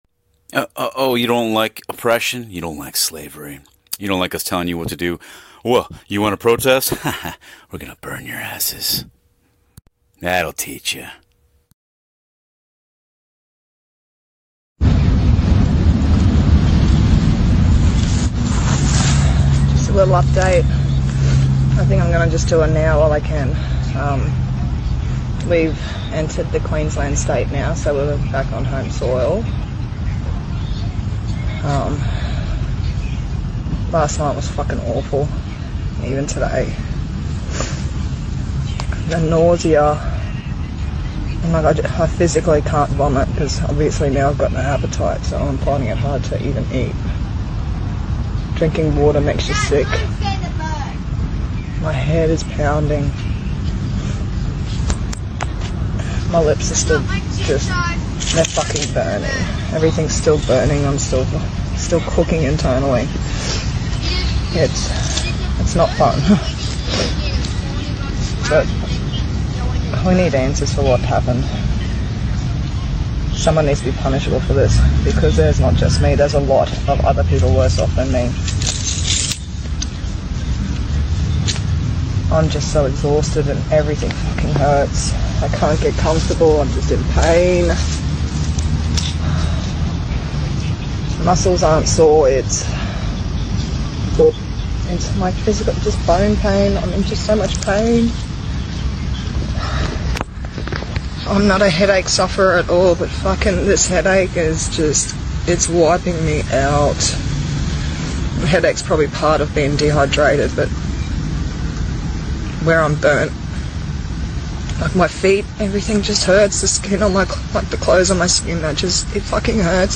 Canberra Microwave victim describes the effects of the weaponry used against the protesters there (19 feb 2022)